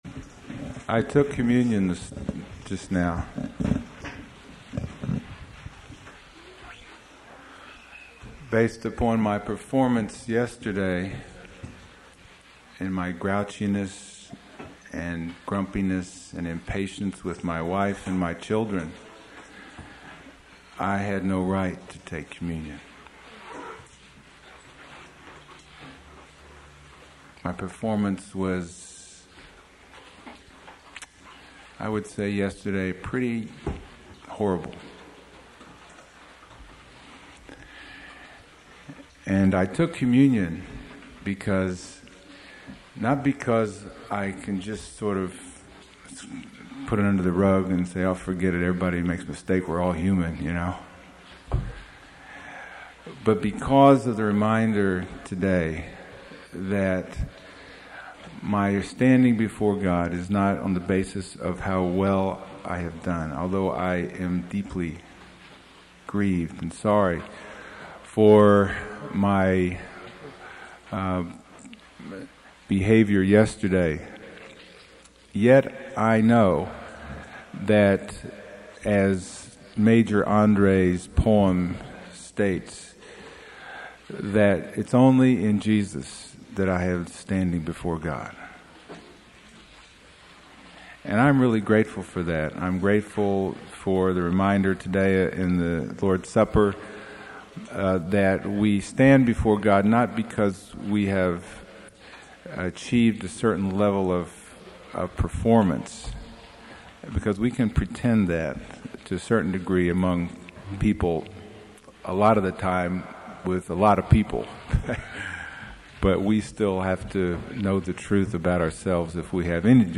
Sermon 16 | The Bronx Household of Faith